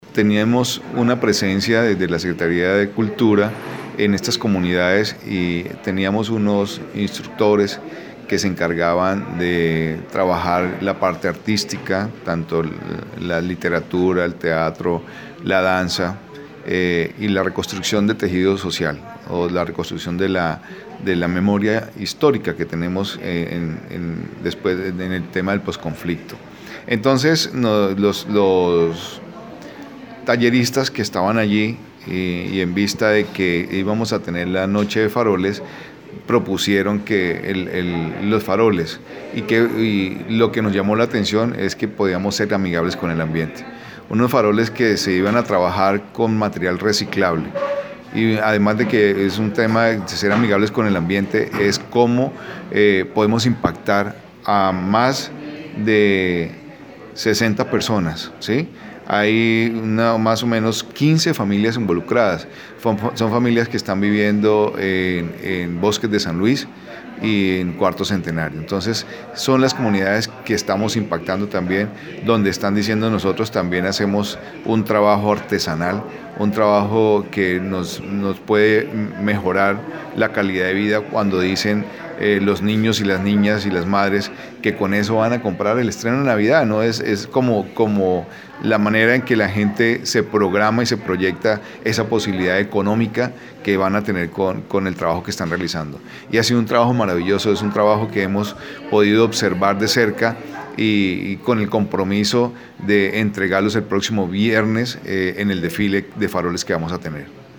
secretario-de-Cultura-de-Neiva-Gilberto-Rojas-Trujillo.-.mp3